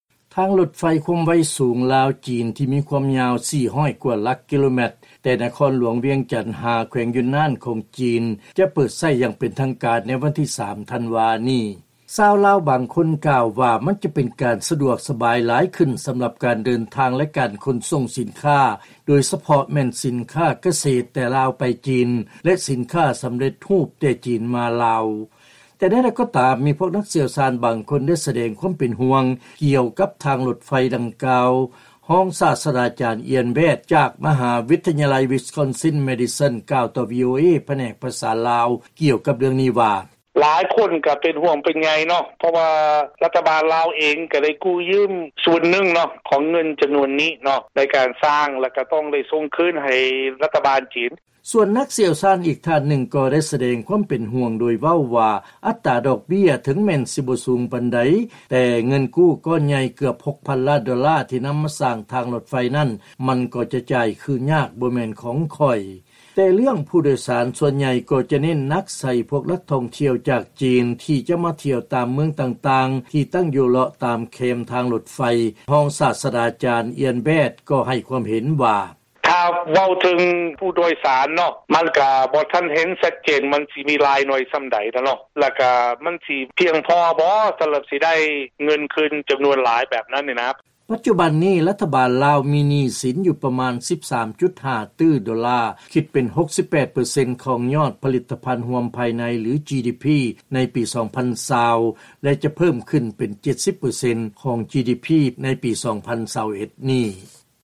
ເຊີນຟັງລາຍງານ ບາງຄົນດີໃຈ ທີ່ລາວມີທາງລົດໄຟ ແຕ່ນັກຊ່ຽວຊານບາງຄົນ ກໍເປັນຫ່ວງ ໃນເລື້ອງໜີ້ສິນ